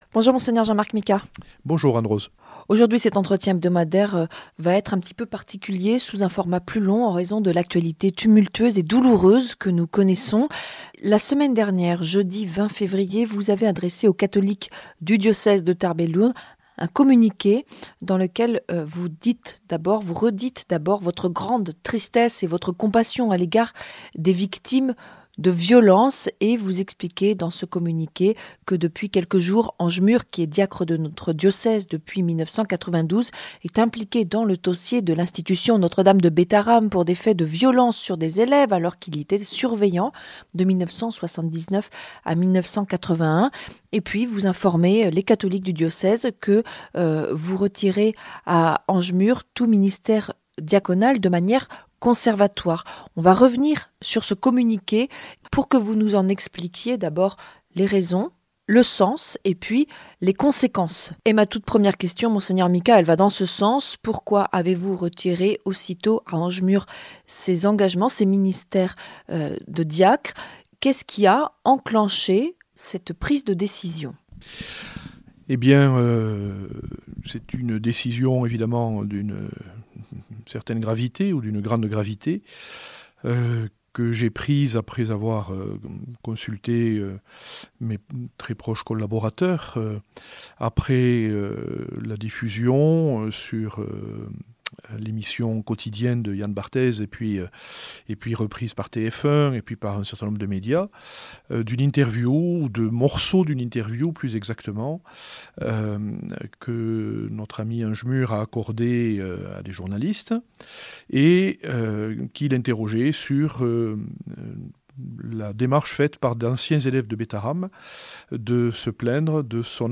Dans cet entretien, Mgr Micas revient sur son dernier communiqué adressé aux catholiques des Hautes-Pyrénées.